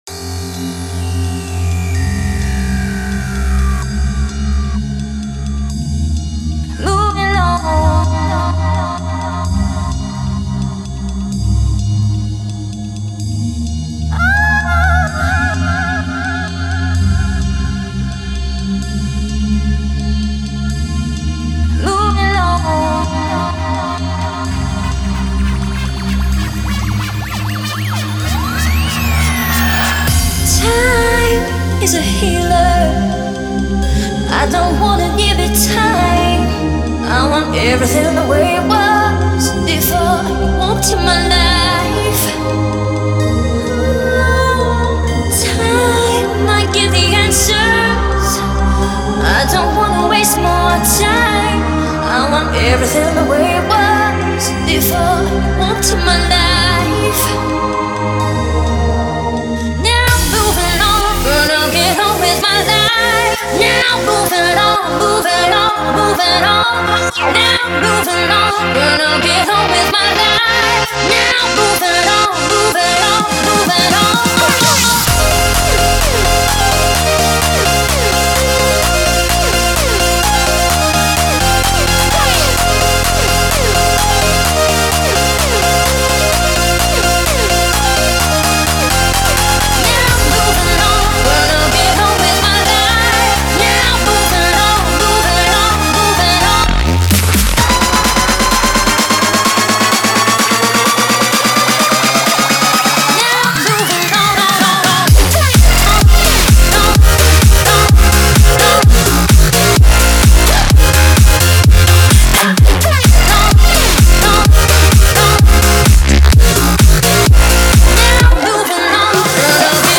Категория: Электро музыка » Электро-хаус